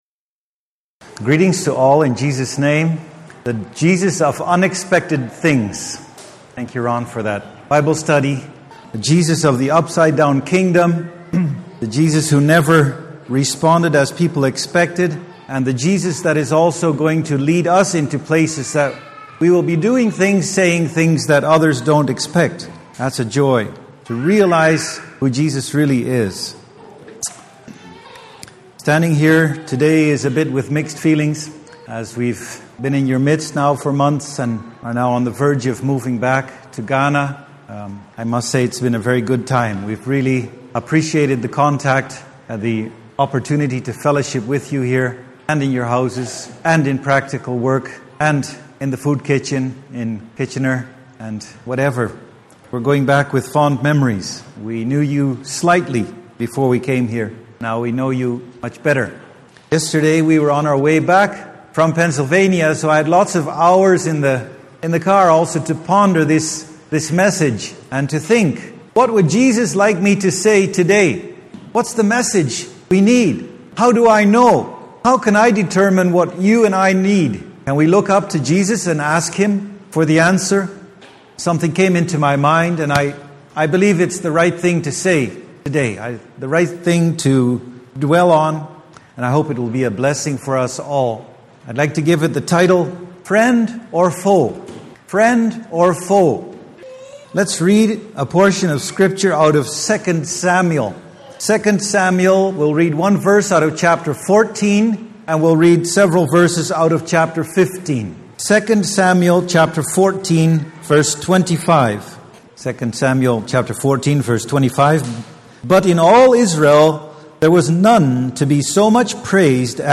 Sunday Morning Sermon Passage: 2 Samuel 14:25-15:12 Service Type